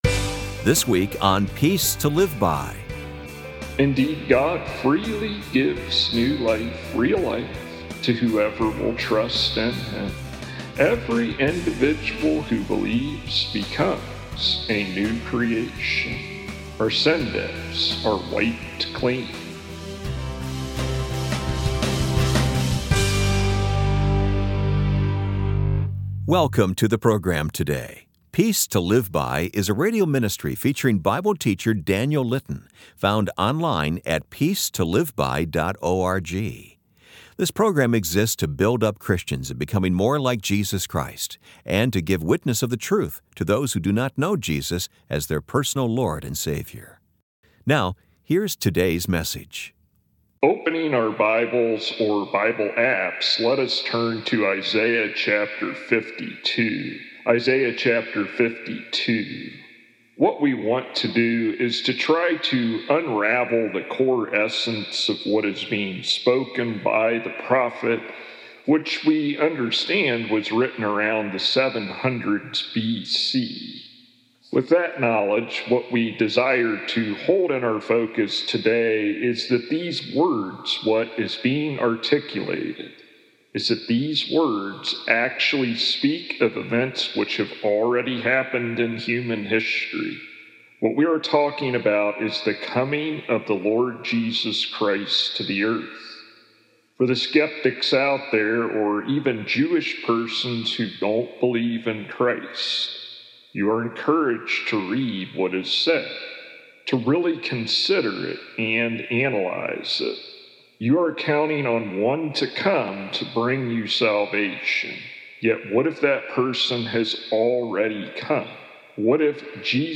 Episode 31 Full Sermon